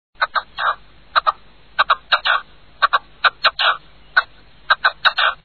Red-legged Partridge
Red-legged-Partridge.mp3